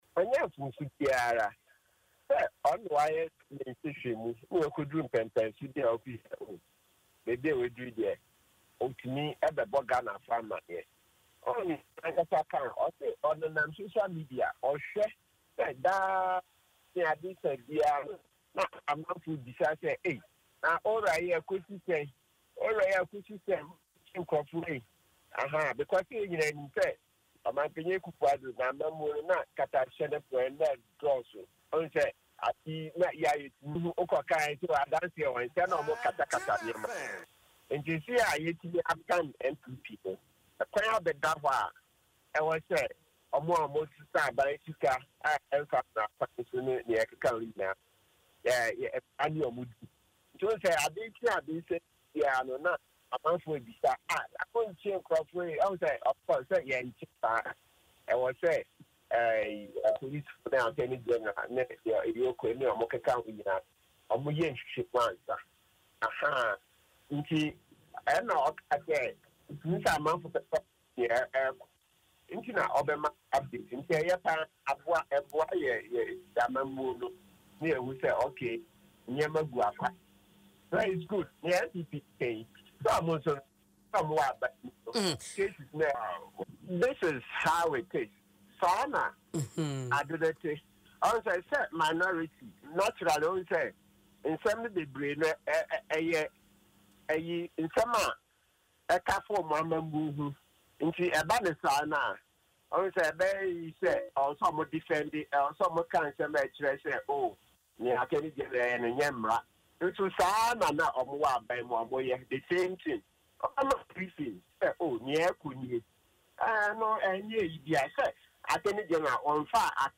Speaking in an interview on Adom FM’s morning show Dwaso Nsem